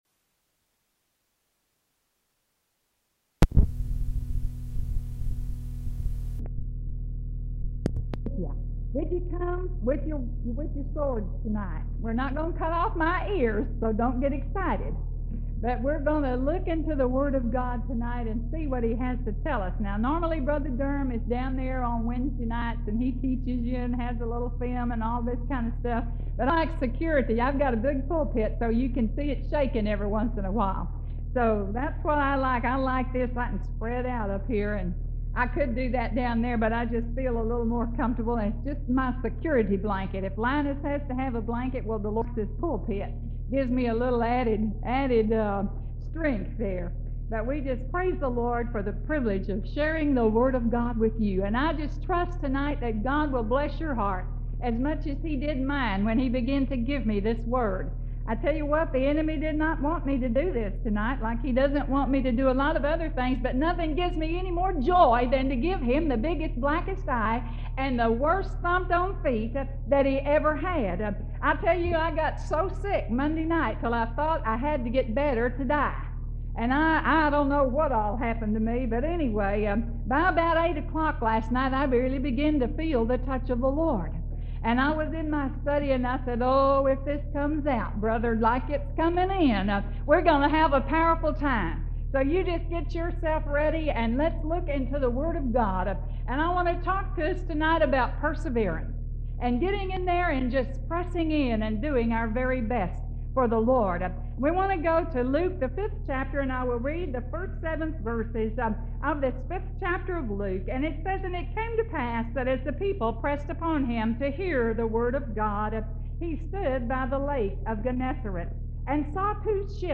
Women Preachers